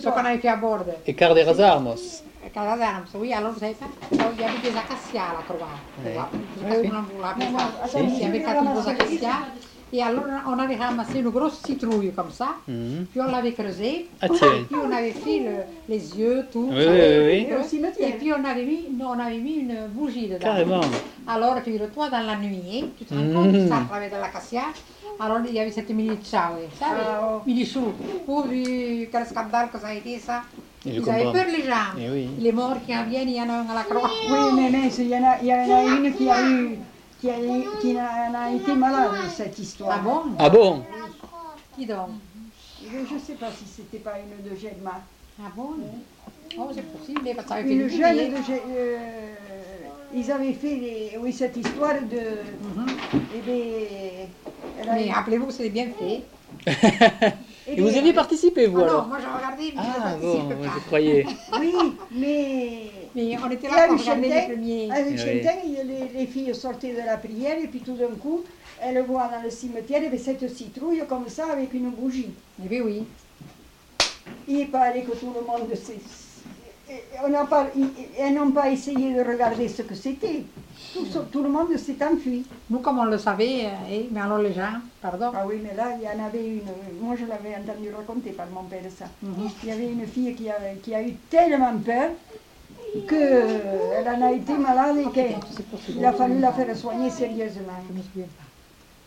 Lieu : Montjoie-en-Couserans
Genre : témoignage thématique